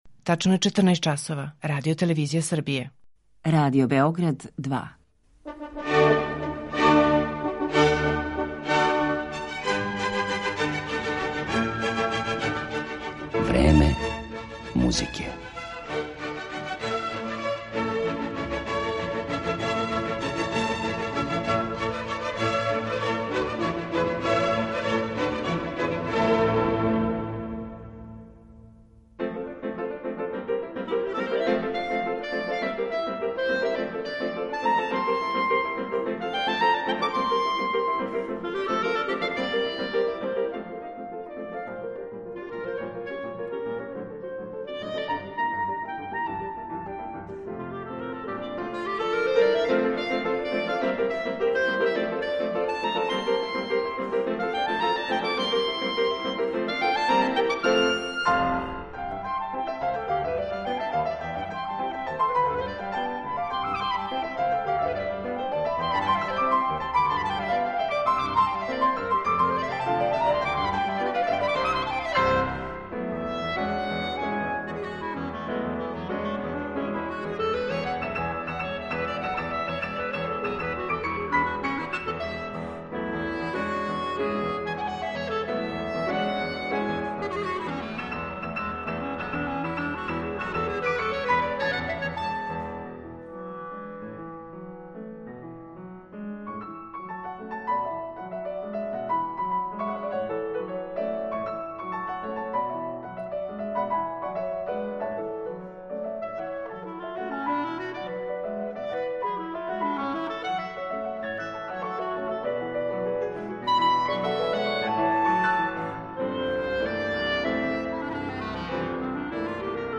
Мајкл Колинс, кларинет
Још од тада, Колинс свира кларинет са неким потпуно магичним састојком.